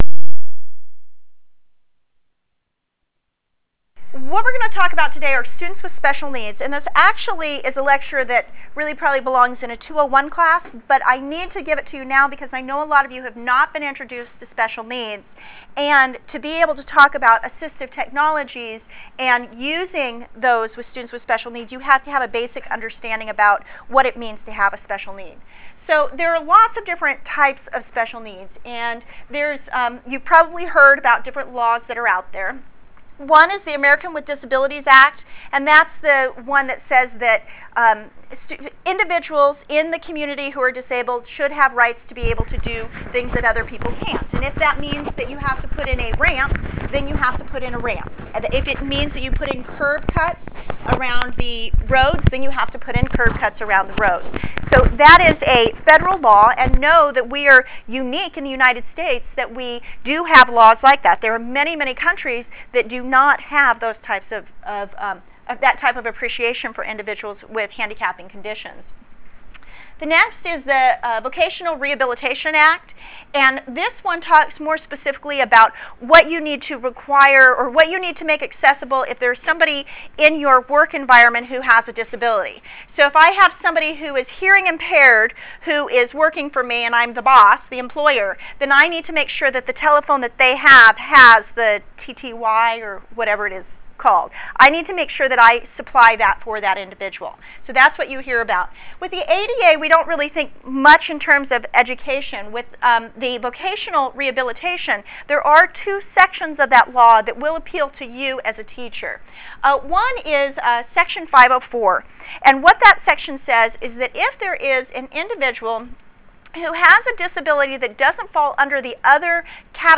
Review the lesson plan , lecture slides , and lecture audio .